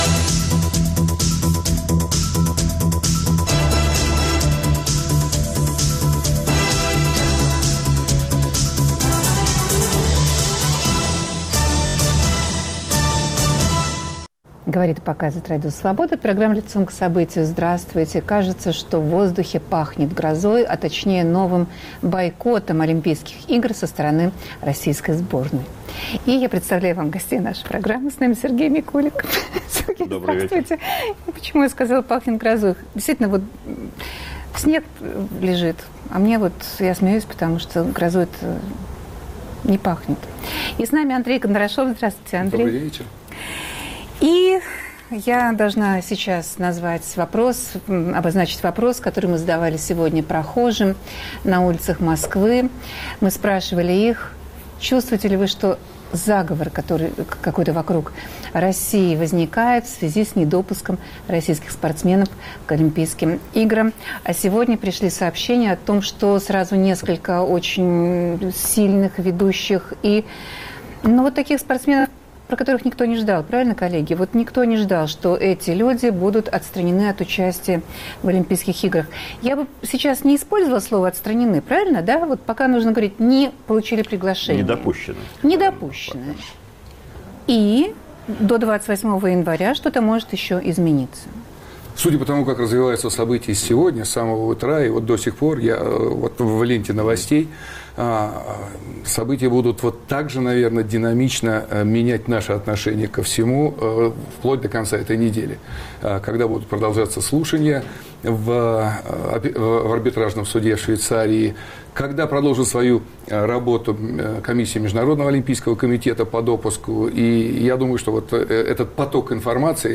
МОК отказывает в приглашении на Олимпийские Игры ряду ведущих российских спортсменов. Как будут дальше развиваться события? В студии спортивные журналисты